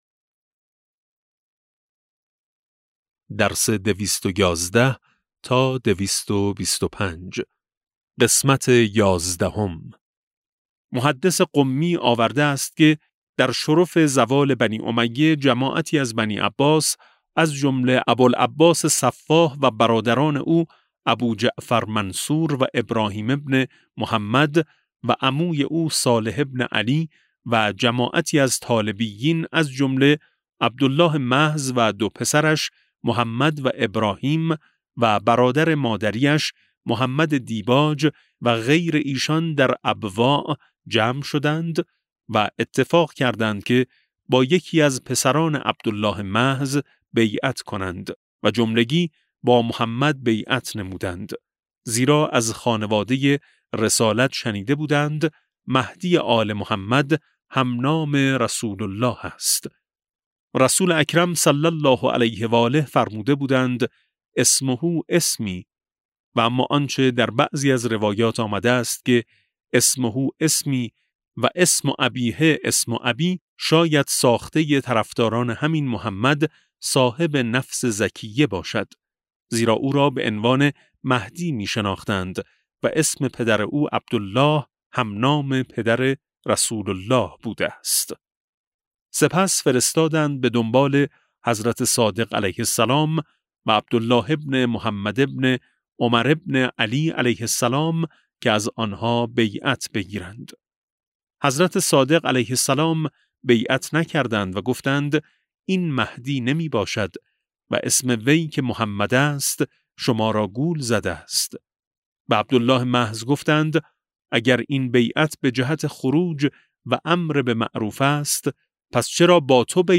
کتاب صوتی امام شناسی ج15 - جلسه11